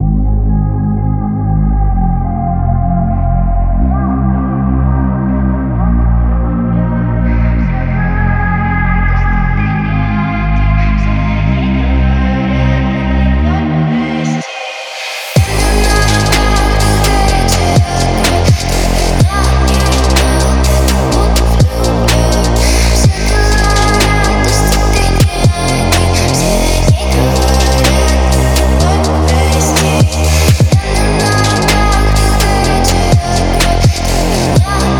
Жанр: Поп / Инди / Русские
# Indie Pop